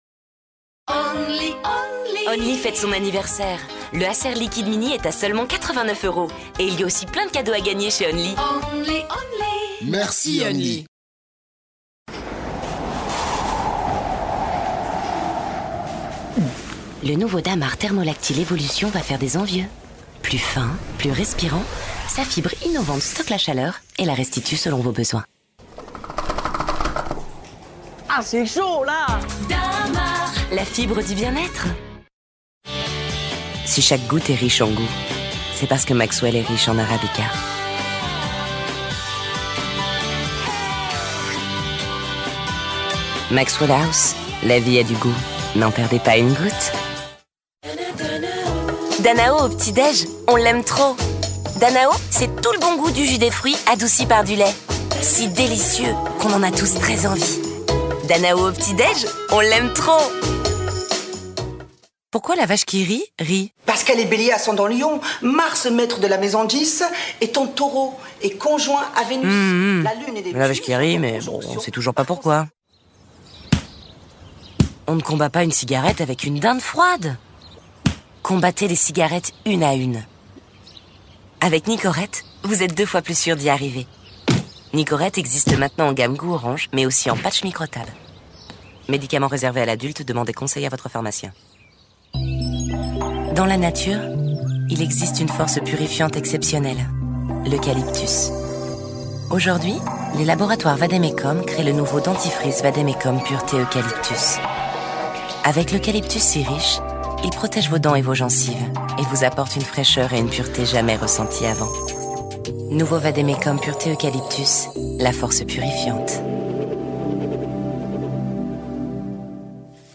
Bande Démo Voix Pub
- Mezzo-soprano